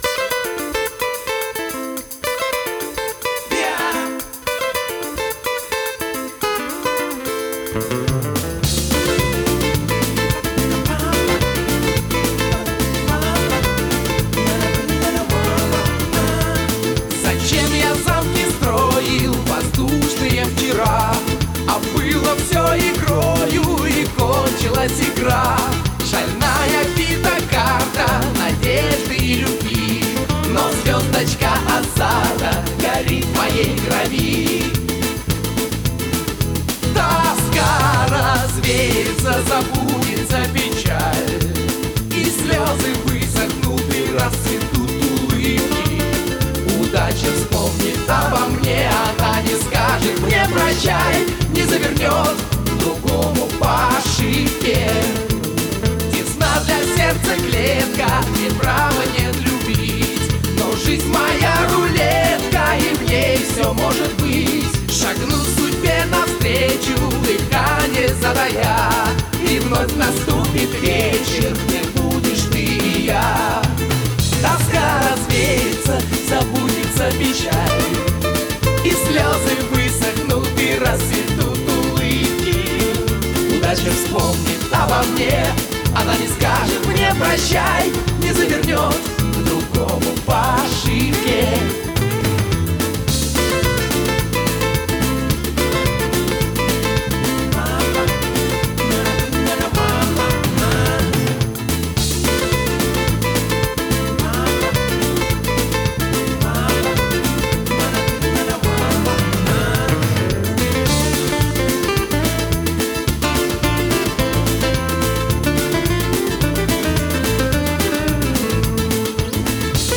Жанр: Попса